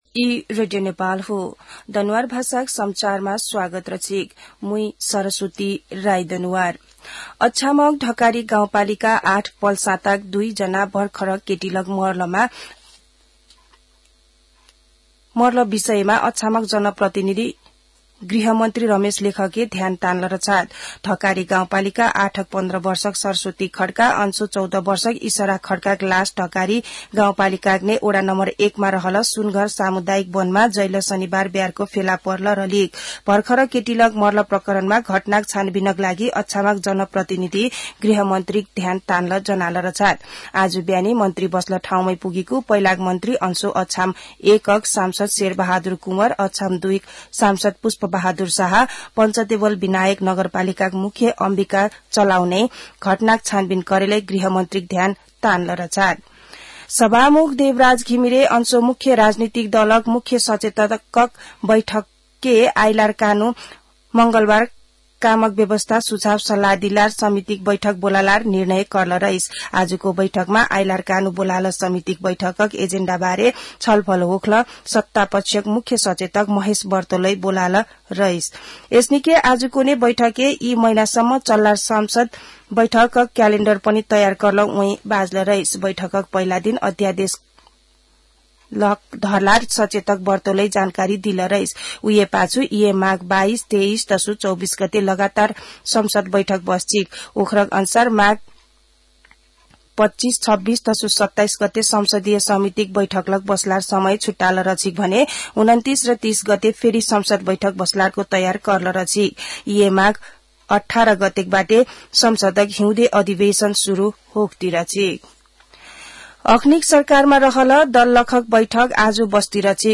दनुवार भाषामा समाचार : १५ माघ , २०८१
Danuwar-news-10.mp3